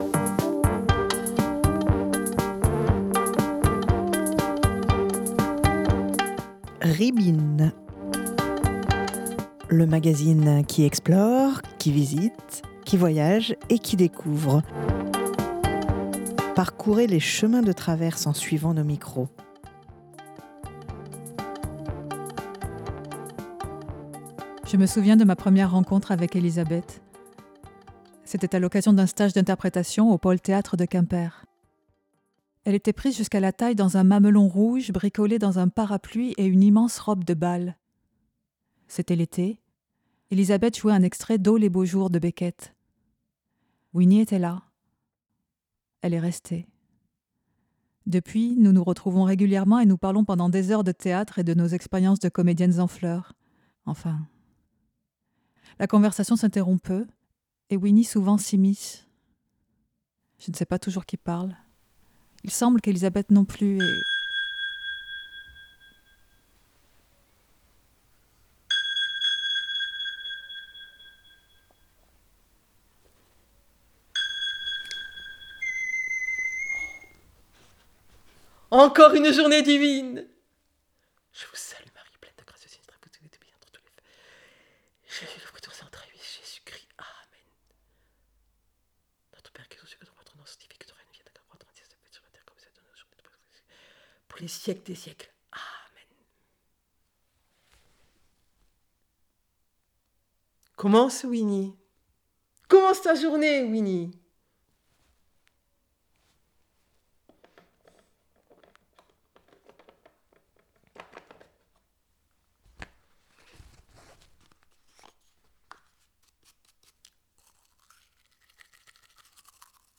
Depuis, nous nous retrouvons régulièrement et nous parlons pendant des heures de théâtre et de nos expériences de comédiennes en fleurs, enfin… La conversation s'interrompt peu et Winnie souvent s'immisce.